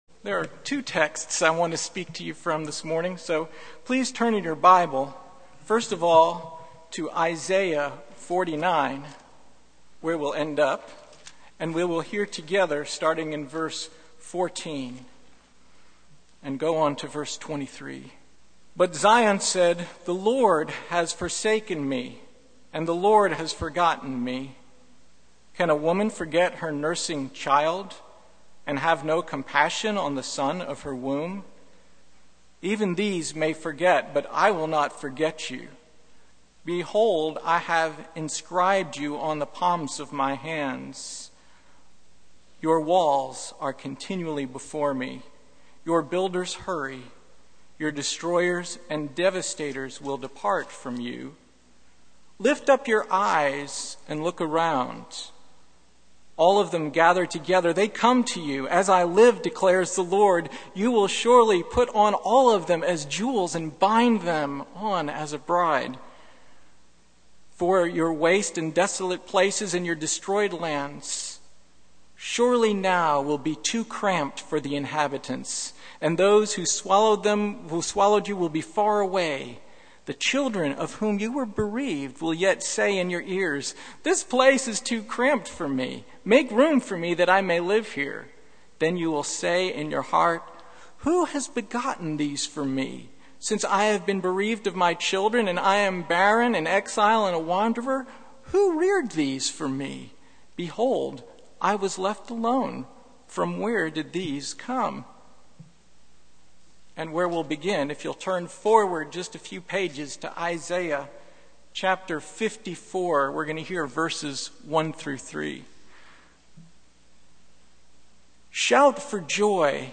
Passage: Isaiah 58:1-12 Service Type: Sunday Morning